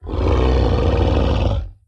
-reduced SFX Quality.
cat.wav